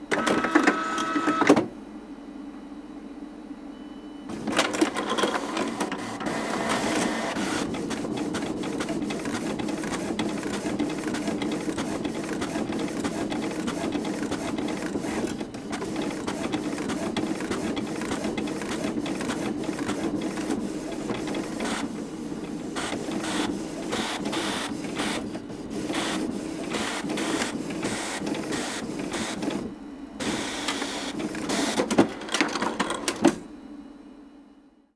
Impresora imprimiendo un documento
impresora
Sonidos: Oficina